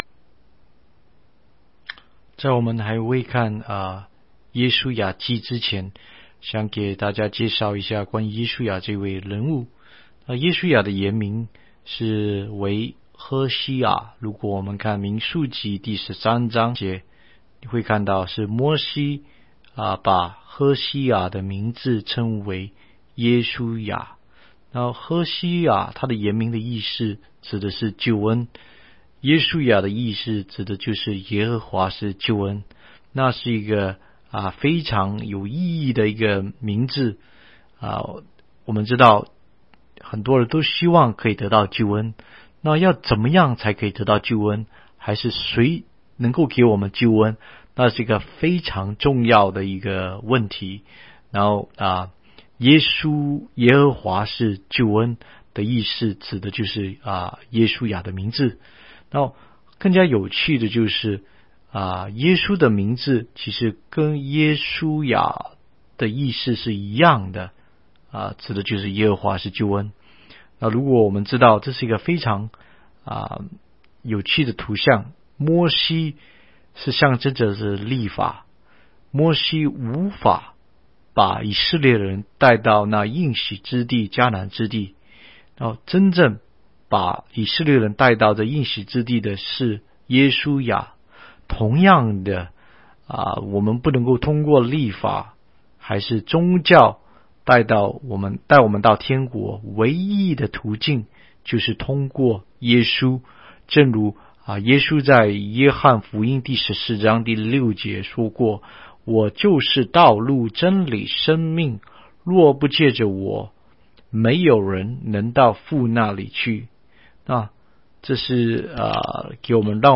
16街讲道录音 - 每日读经-《约书亚记》1章